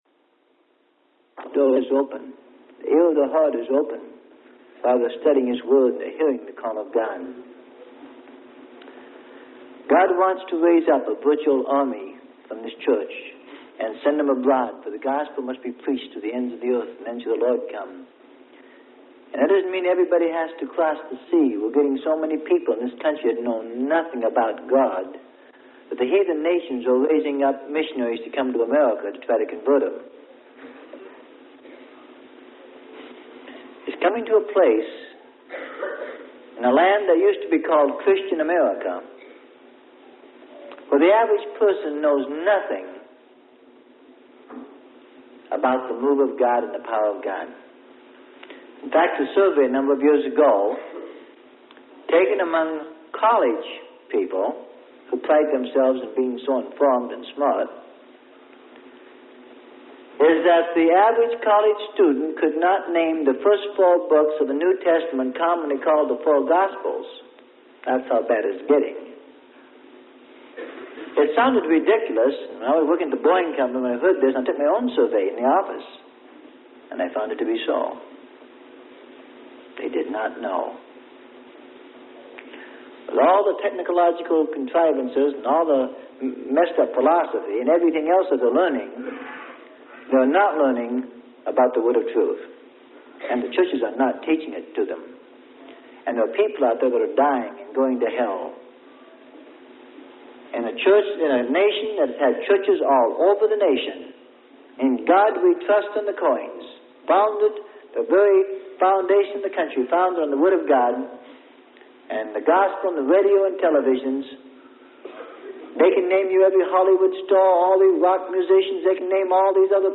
Sermon: Challenge To Hear The Word Of The Lord.